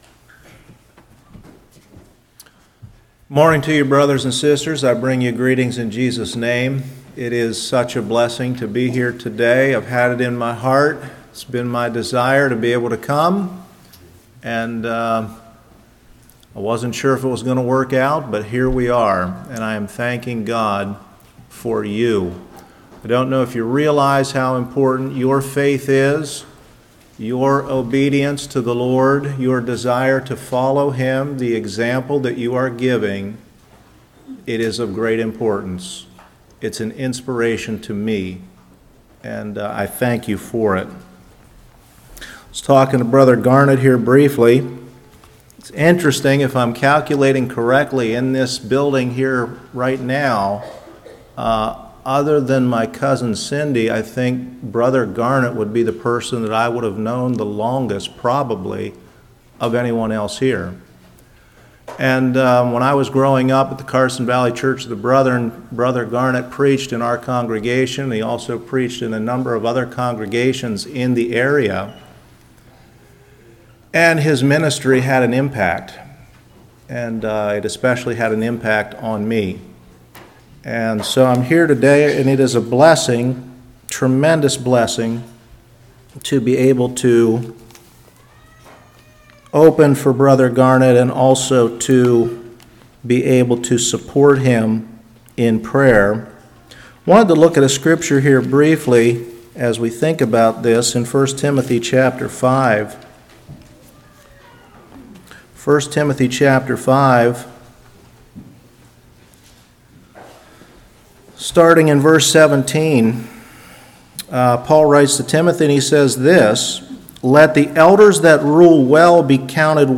Passage: Hebrews 9:16-24 Service Type: Revival